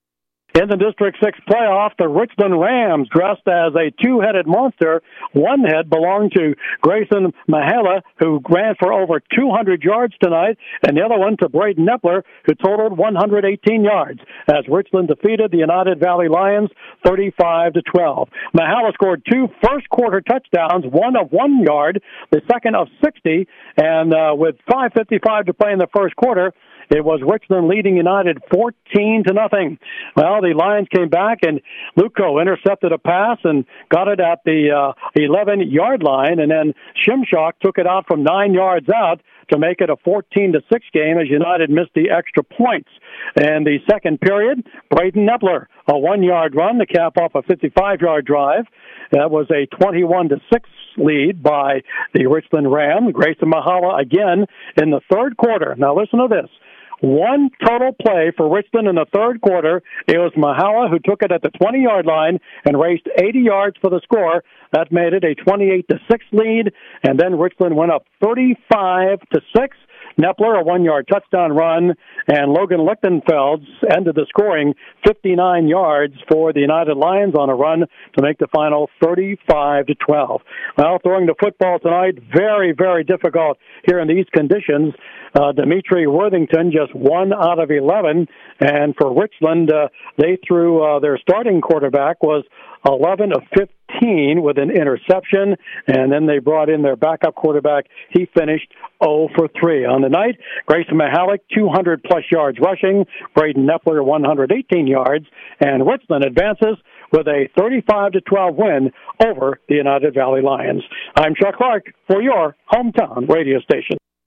had the recap on U92.5 FM.
hsfb-united-valley-vs-richland-recap.mp3